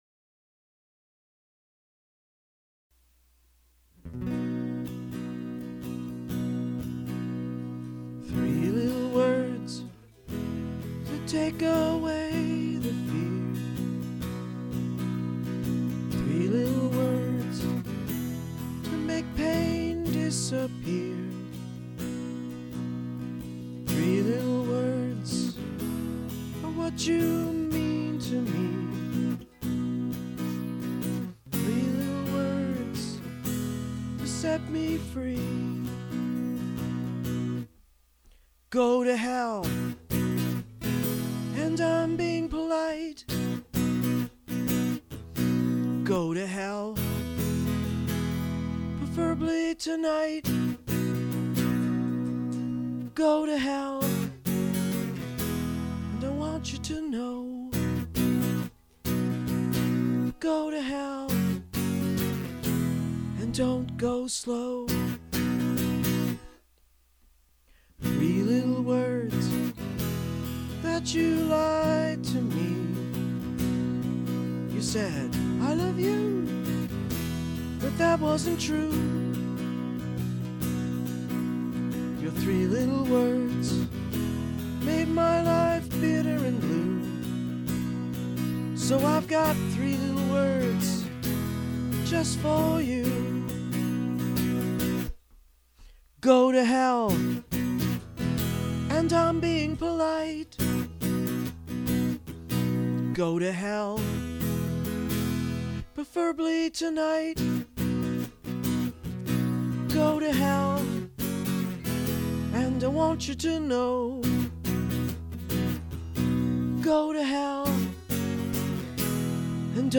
Recorded in Low-Fidelity December 2005-February 2006 at
Guitar
and  Drum Machine